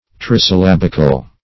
Search Result for " trisyllabical" : The Collaborative International Dictionary of English v.0.48: Trisyllabic \Tris`yl*lab"ic\, Trisyllabical \Tris`yl*lab"ic*al\, a. [L. trisyllabus, Gr.